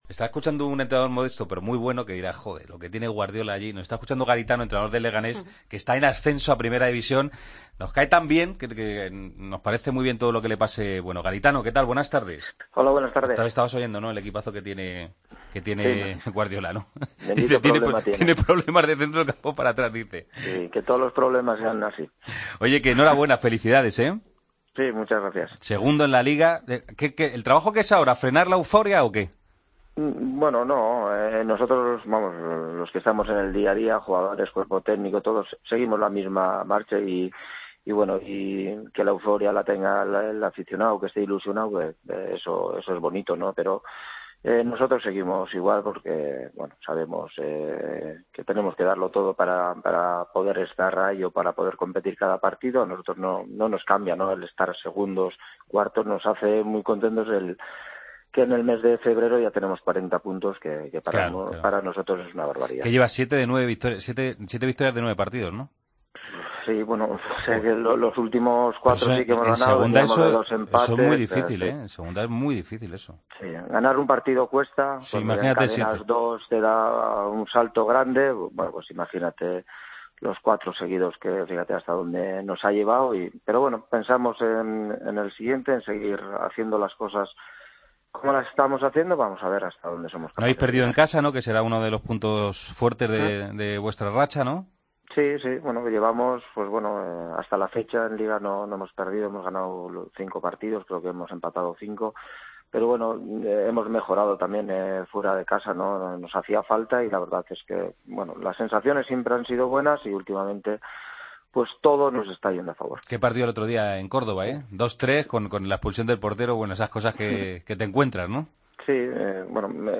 El entrenador del Leganés valoró el segundo puesto en la clasificación del conjunto madrileño en la Liga Adelante: "Todo nos sale a favor".